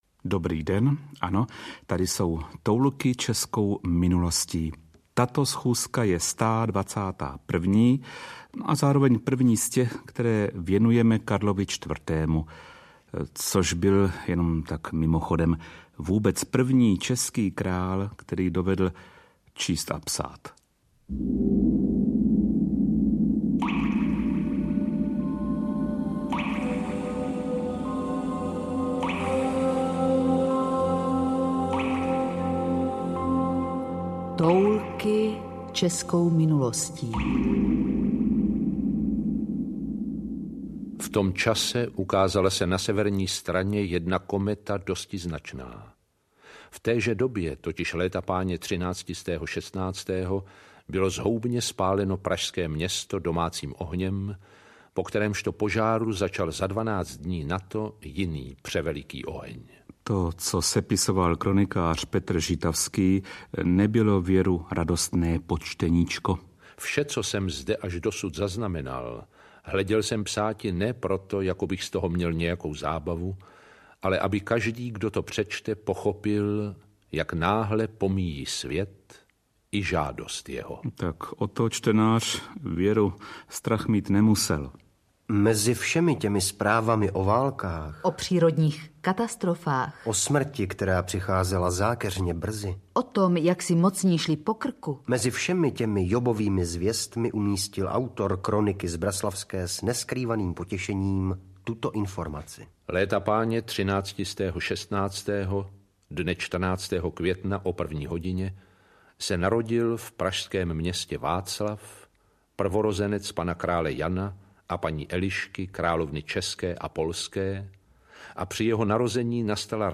Audio knihaToulky českou minulostí - speciál Karel IV.
Ukázka z knihy
Rozhlasová verze využívá kvality knihy a rozšiřuje její účinek o sílu mluveného slova.